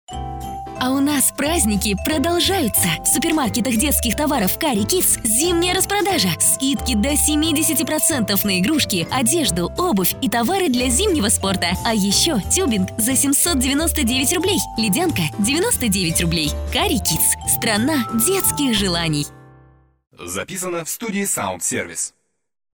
Женщина
Молодой
быстрый
Приглушенный
Средний
7451_reklama-1.mp3